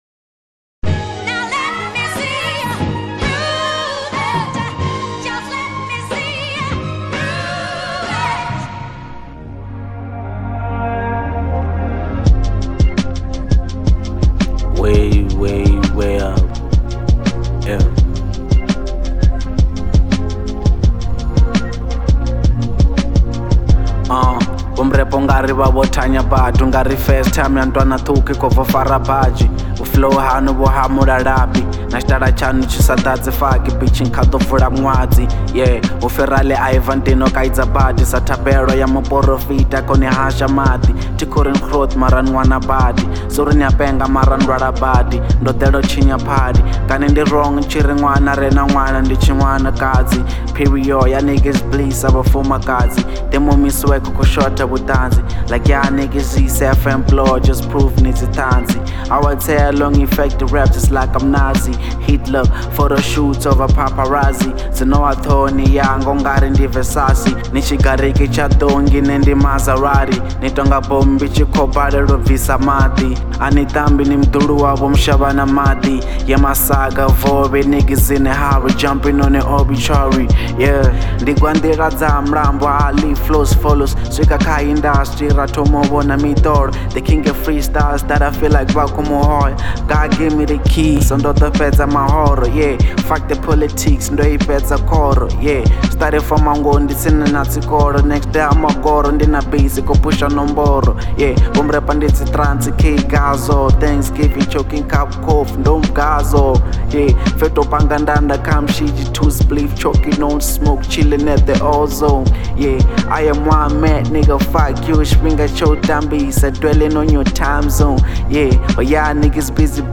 05:40 Genre : Venrap Size